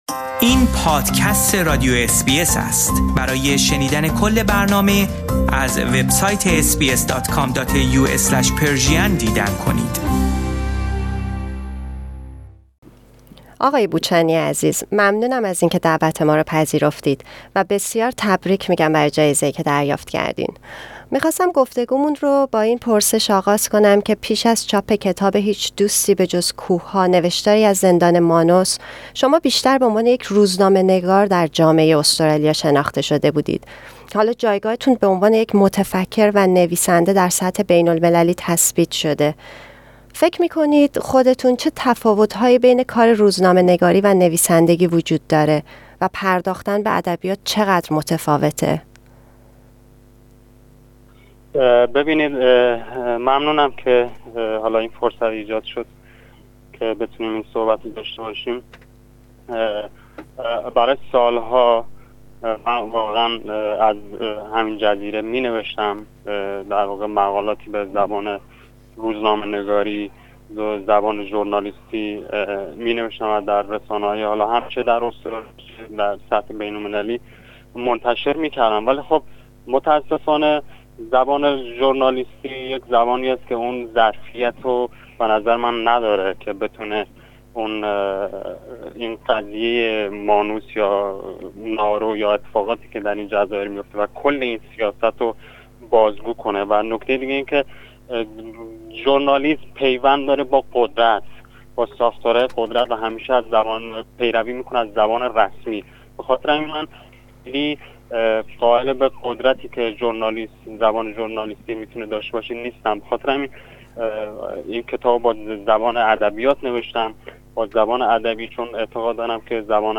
Iranian Kurdish journalist and writer Behrouz Boochani won the prestigious Victorian Premier's Literary Award. This is an interview with Behrouz about his book.